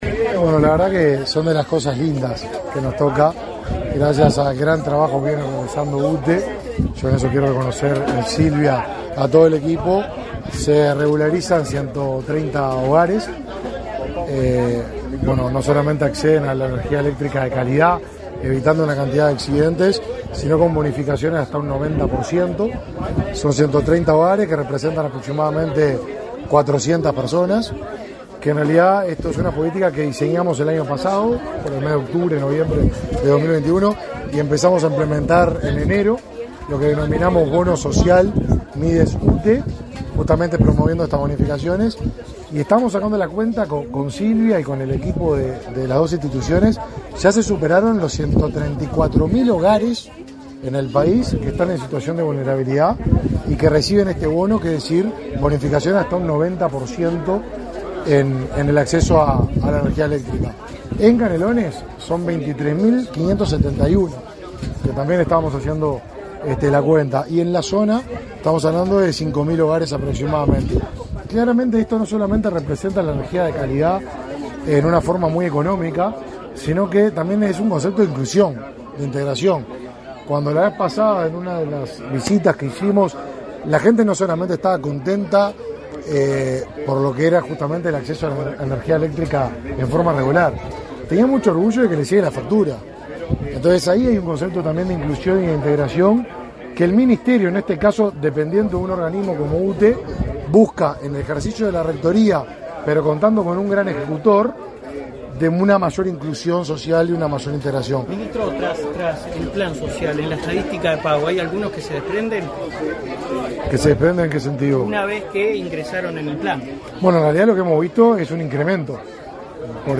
Declaraciones a la prensa del ministro de Desarrollo Social
El ministro de Desarrollo Social, Martín Lema, participó este 28 de octubre en la inauguración de obras de regularización del acceso a la energía